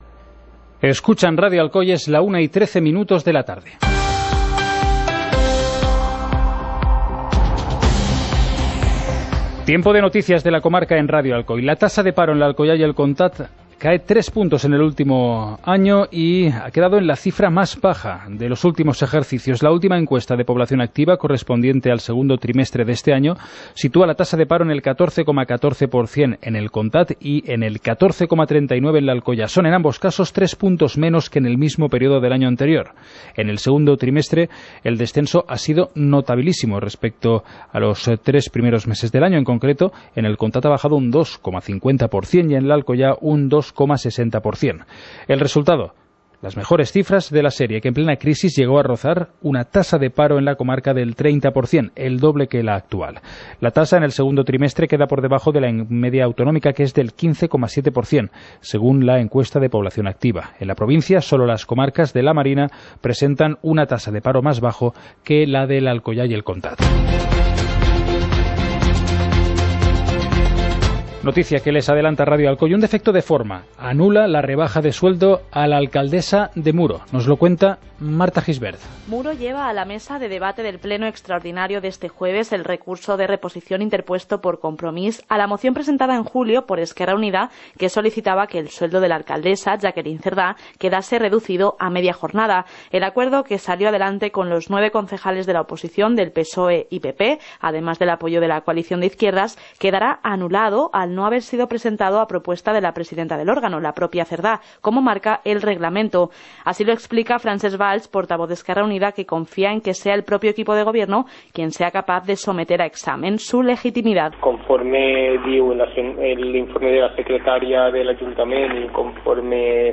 Informativo comarcal - miércoles, 19 de septiembre de 2018